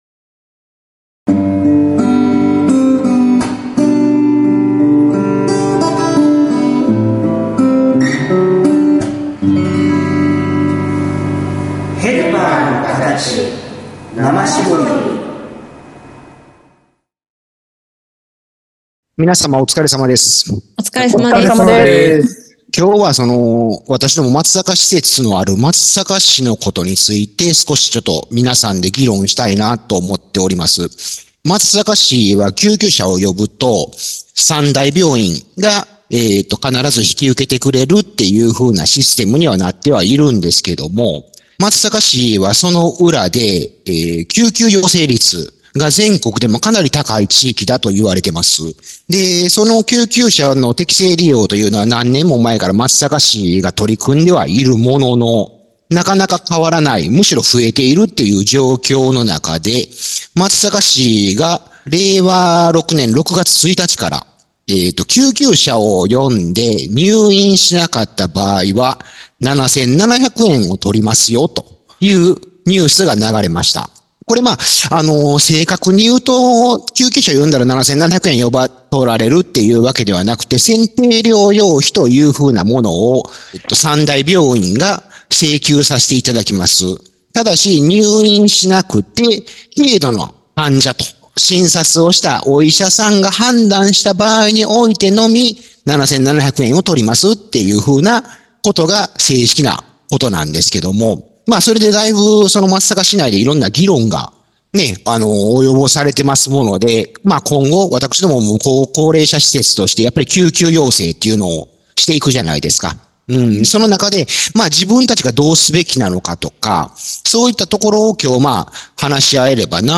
＜今週のテーマ＞ 令和６年６月に松阪市で 導入される『救急車利用に 係る選定療養費徴収制度』 についてのクロストークを 配信致します。全国的にも 話題の本ニュースを、地元 の我々はどう見るのか。